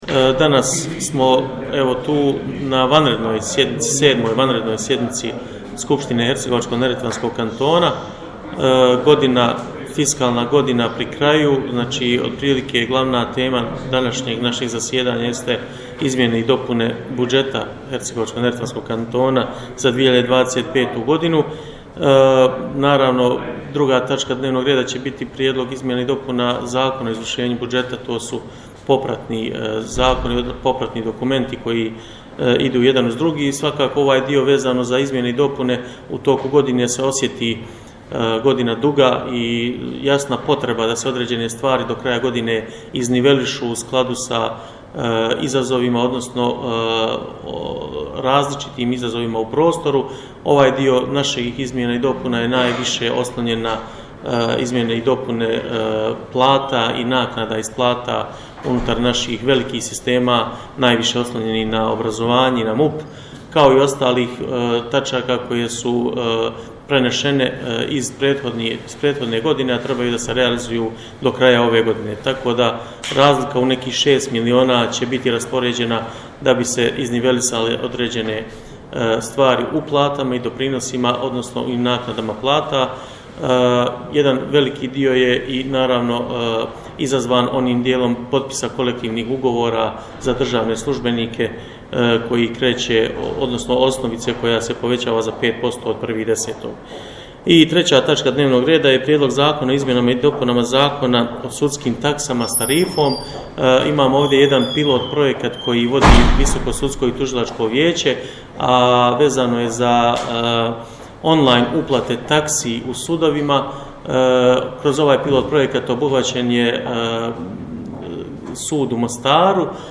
Predsjedavajući Skupštine HNK-a Džafer Alić je pojasnio razloge nivelizacije osnovice u velikim sistemima kao što su obrazovanje i MUP.